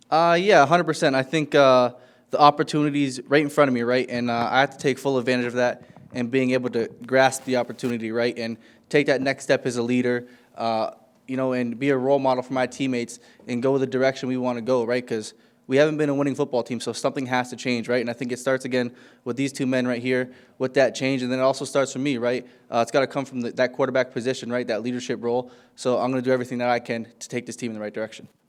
After signing a three year contract extension last week to remain with the “Green n Gold” quarterback Tre Ford spoke to media discussing his extension.